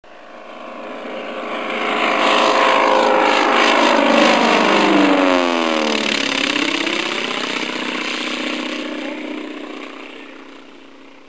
SOUND BMW 132A – Sternmotor